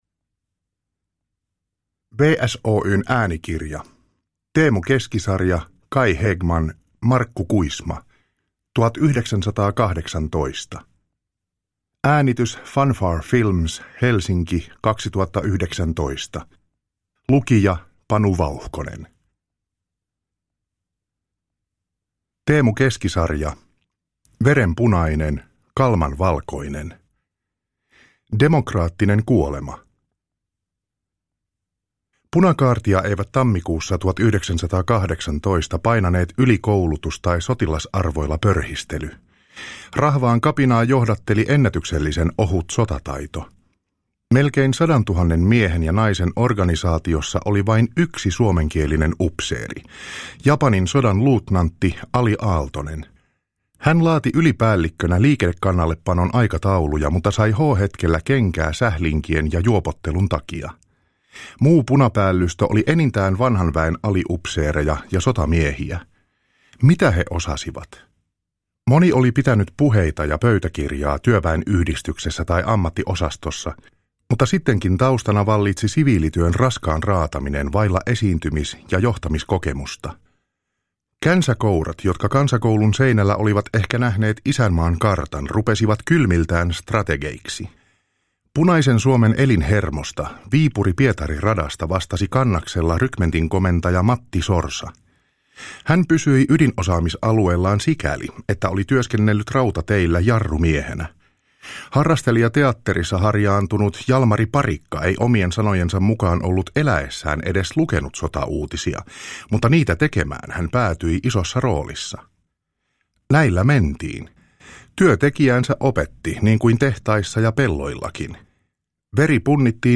1918 – Ljudbok